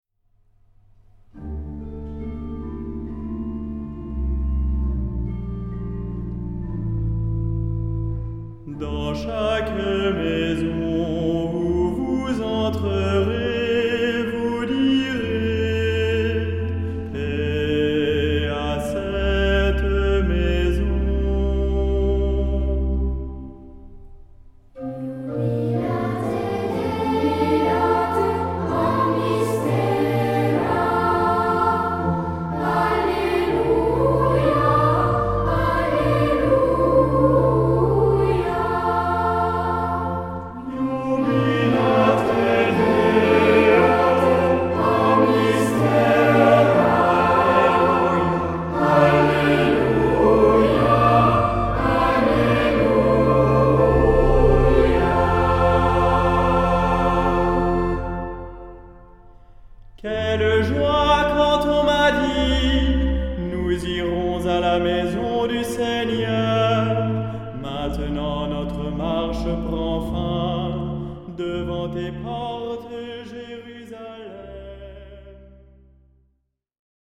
Salmodia.